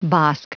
Prononciation du mot bosk en anglais (fichier audio)
Prononciation du mot : bosk